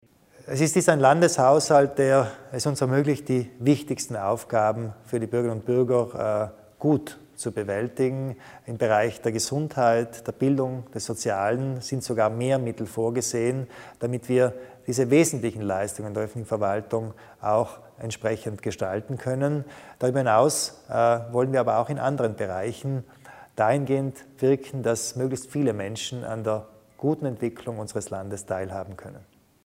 Landeshauptmann Kompatscher zu den Schwerpunkten des Haushalts 2018